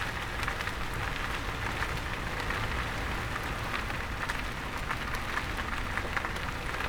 tires-rolling-asphalt.wav